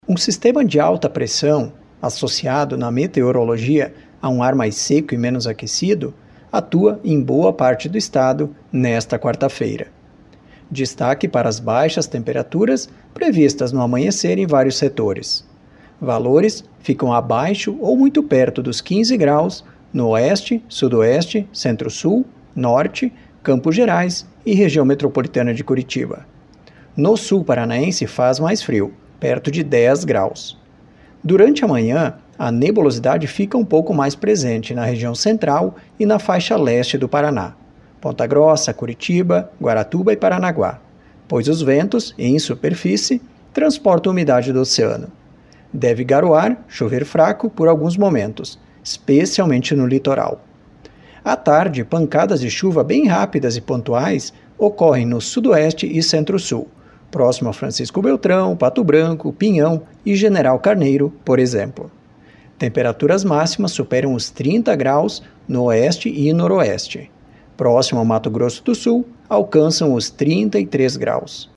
Ouça a previsão detalhada com o meteorologista do Simepar